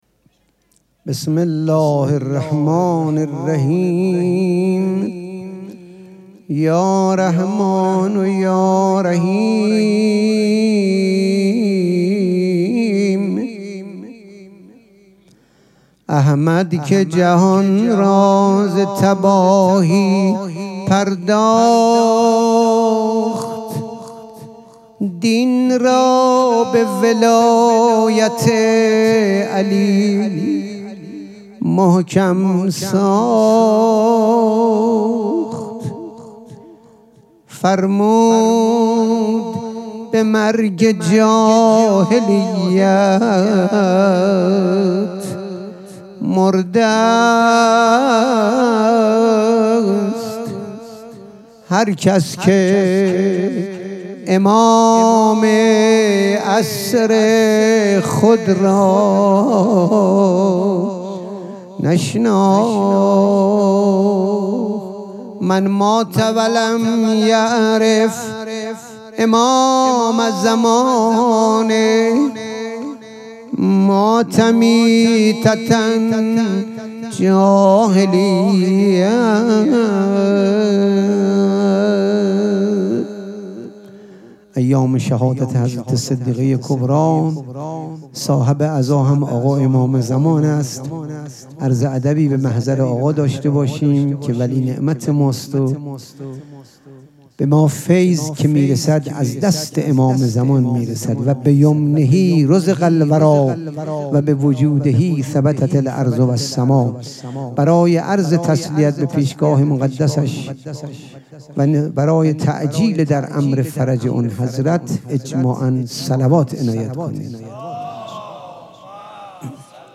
سبک اثــر پیش منبر